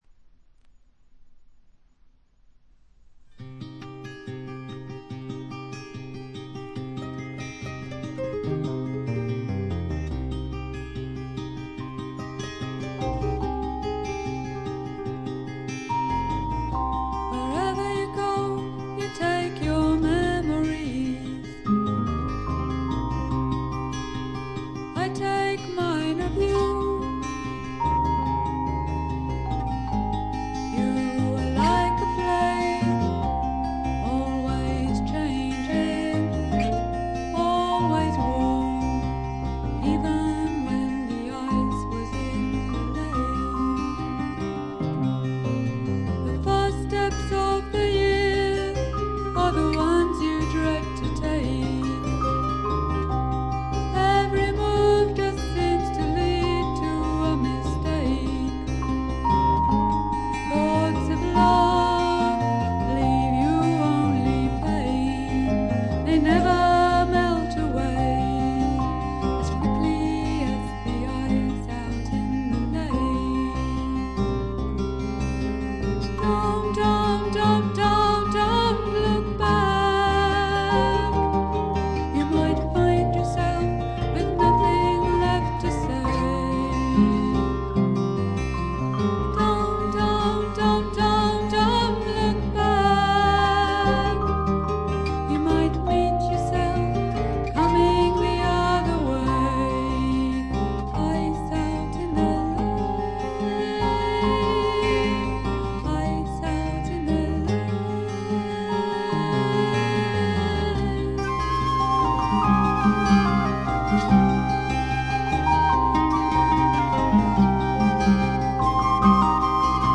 ホーム > レコード：英国 SSW / フォークロック
ところどころで軽いチリプチ程度。
少しざらついた美声がとても心地よいです。
試聴曲は現品からの取り込み音源です。